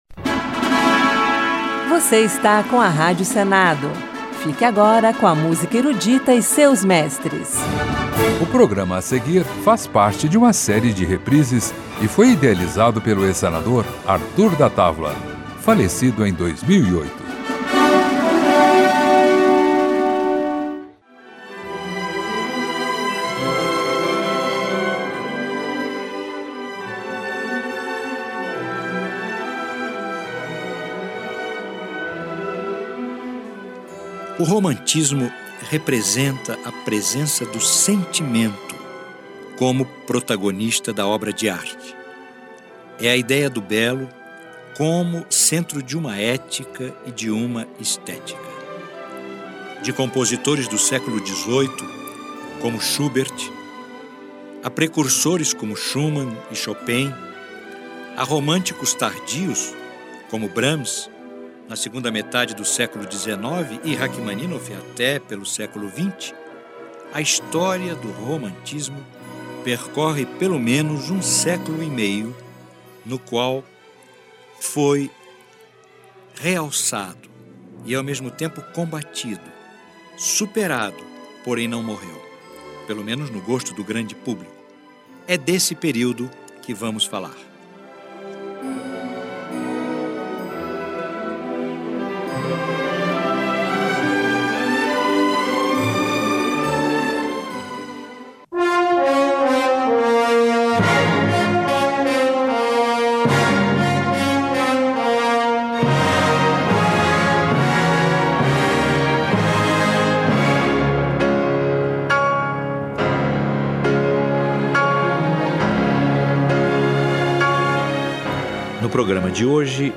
Música Erudita
Período romântico